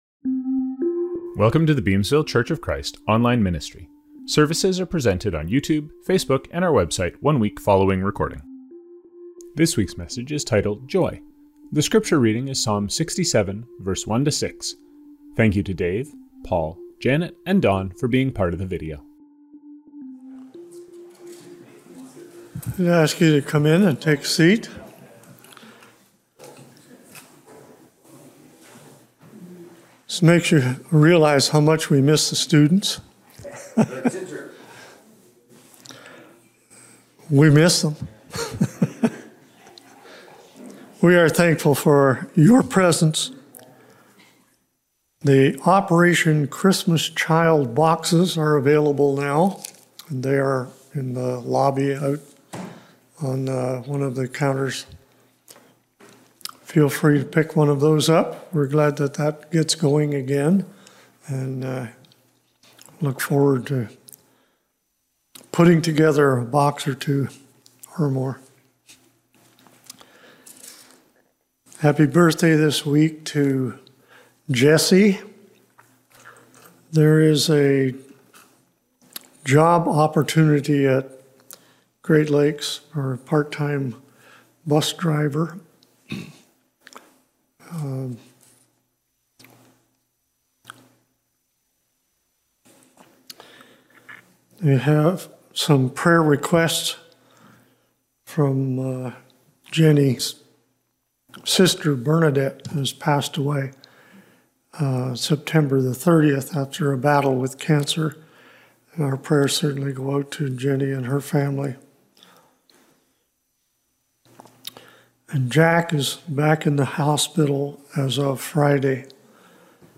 Scriptures from this service: Communion: Philippians 3:10.